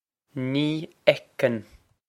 Pronunciation for how to say
Nee ec-n
This is an approximate phonetic pronunciation of the phrase.